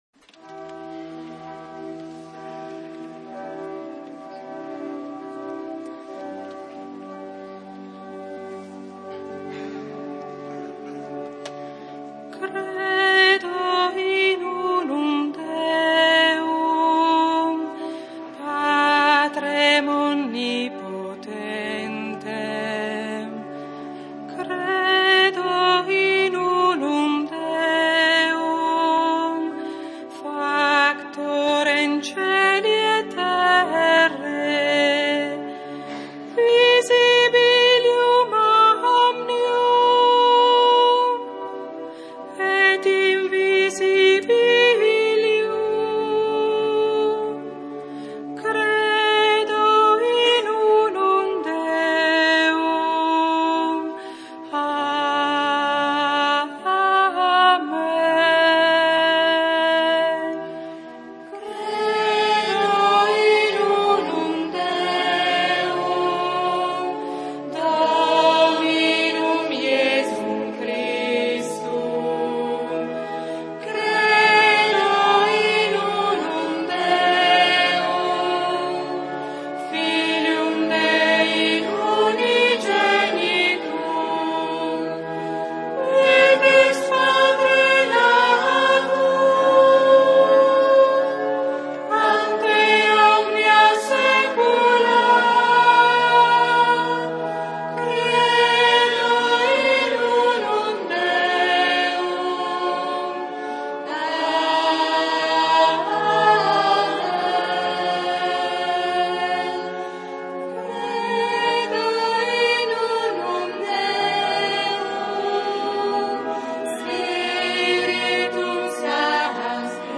V domenica di Pasqua (Gli ultimi colloqui)
canto: